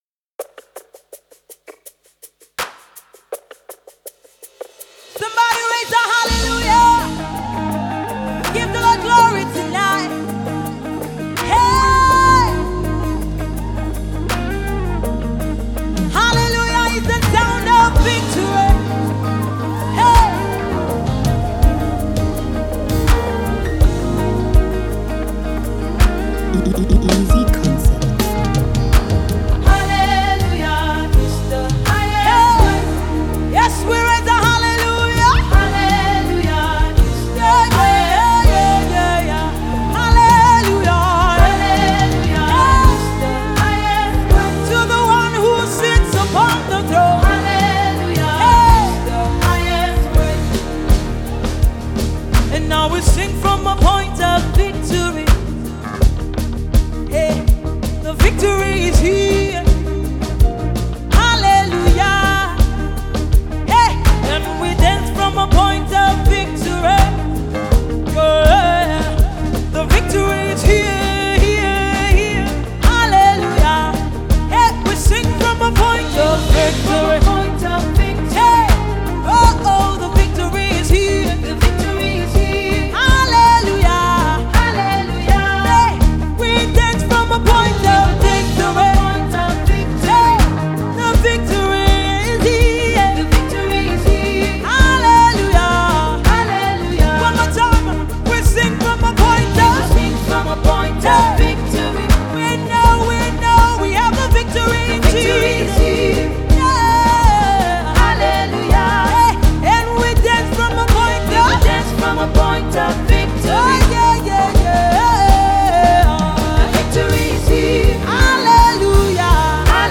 Mp3 Gospel Songs
a spirit of joy and celebration